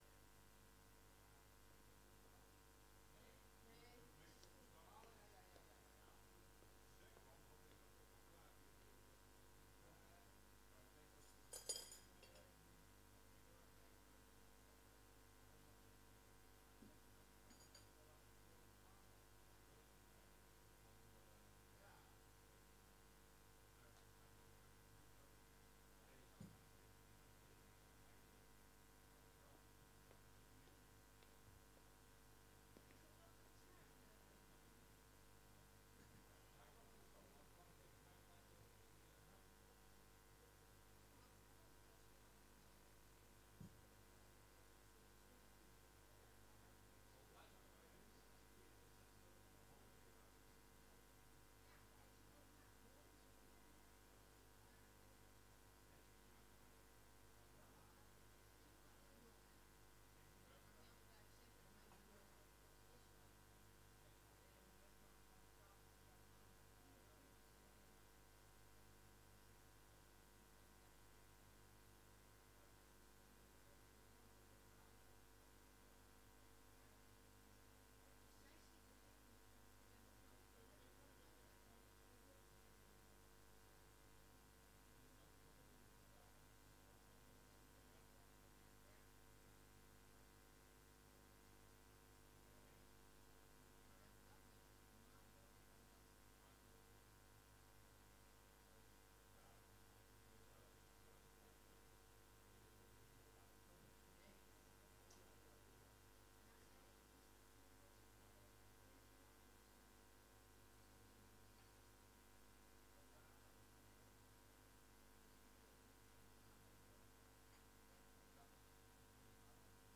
Raadsvergadering 23 april 2020 19:30:00, Gemeente Dronten
Download de volledige audio van deze vergadering